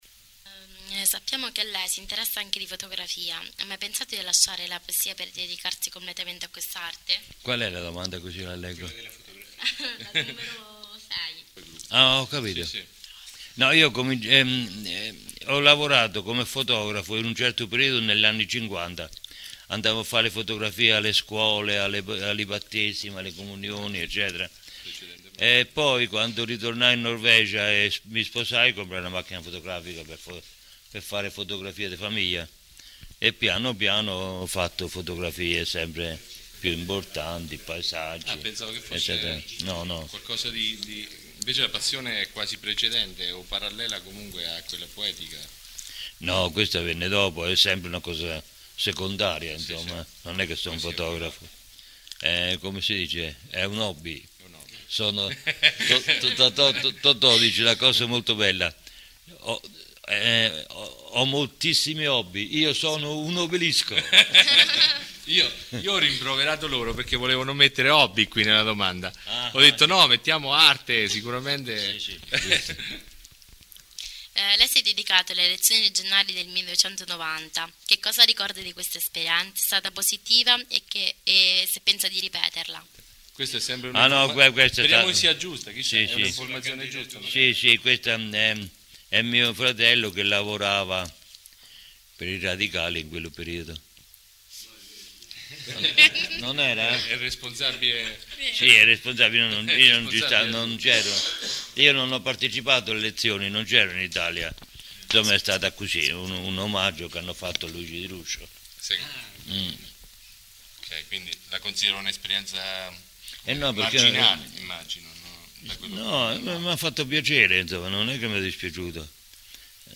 La registrazione è del 2004 ed è stata effettuata dai ragazzi dell’IPC di Cupra Marittima e dai loro insegnanti per Dream Radio Stream, la loro web radio, fu la prima in Italia di un istituto d’istruzione superiore.